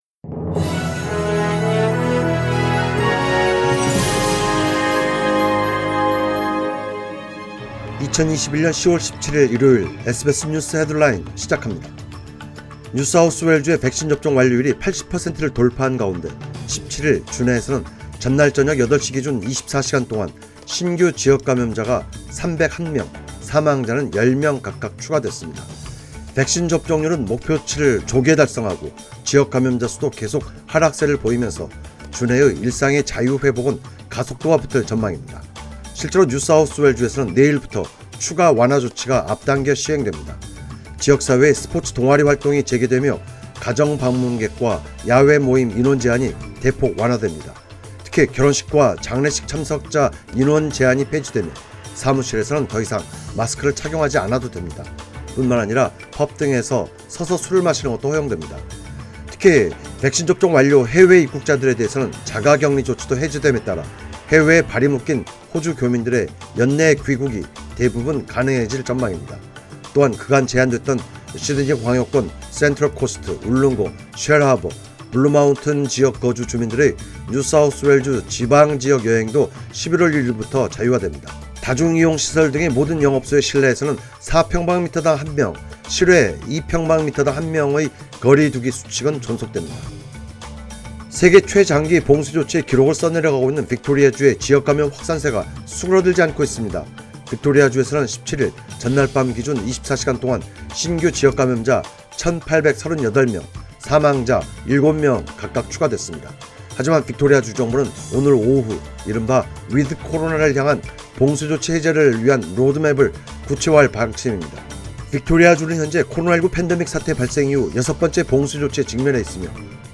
2021년 10월 17일 일요일 SBS 뉴스 헤드라인입니다.